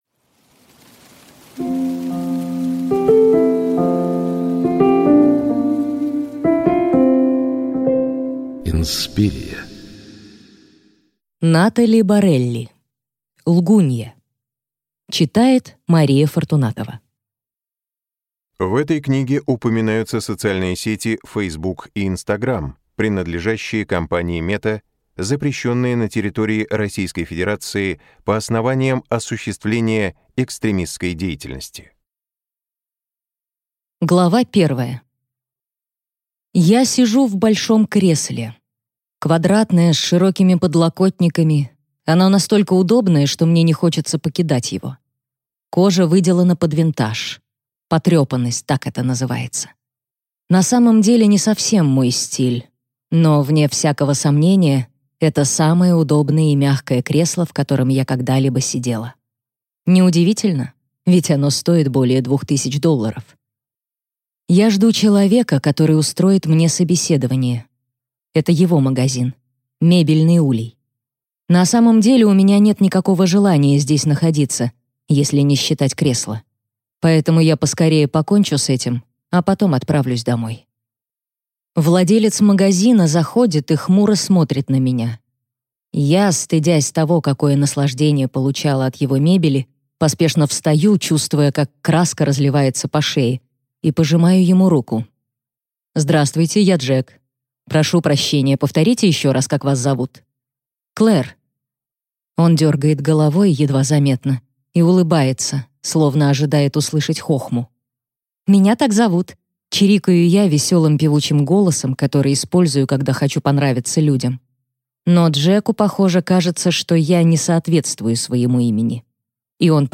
Аудиокнига Лгунья | Библиотека аудиокниг